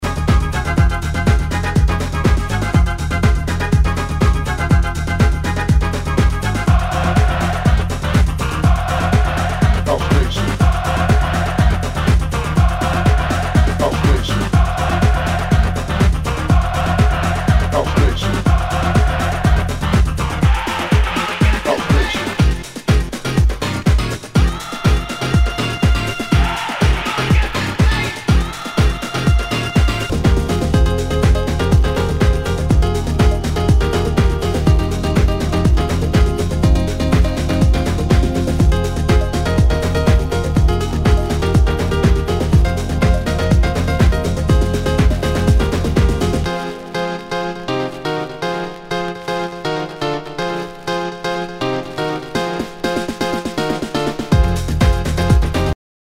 HOUSE/TECHNO/ELECTRO
ナイス！.ユーロ・テクノ・クラシック！